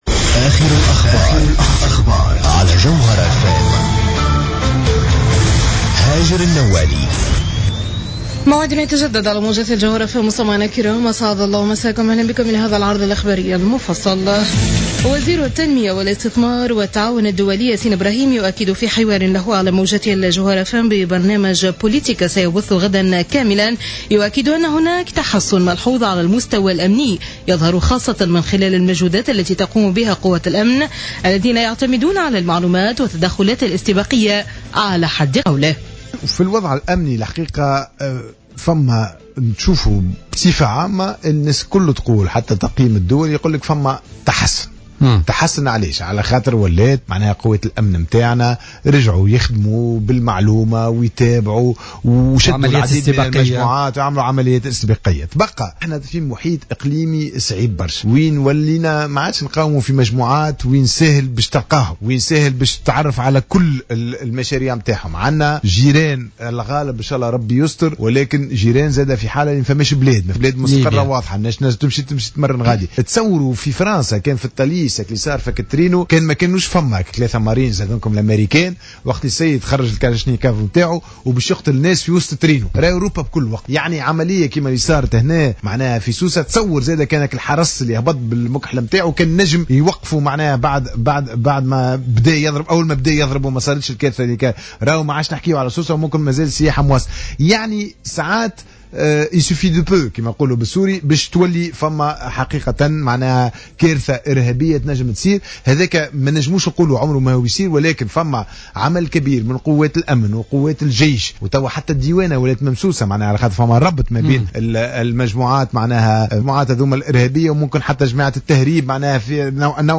Journal Info 00h00 du lundi 14 Septembre 2015